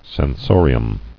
[sen·so·ri·um]